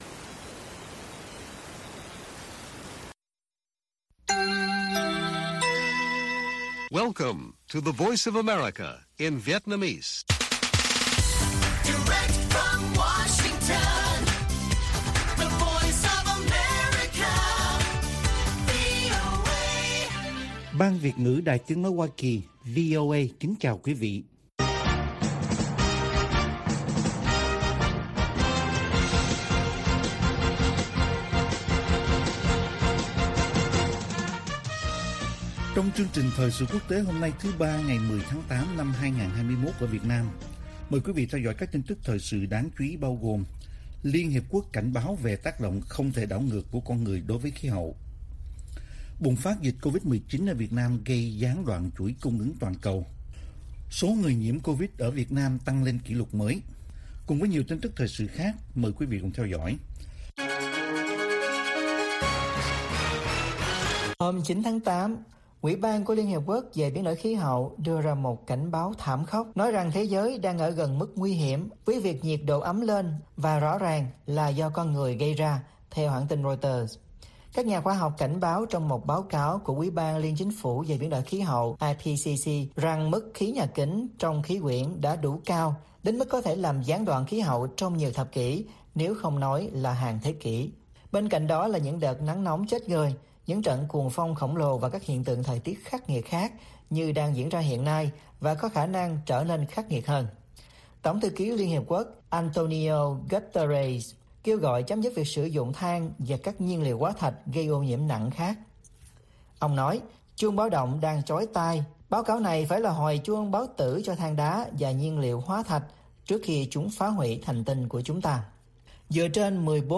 Bản tin VOA ngày 10/8/2021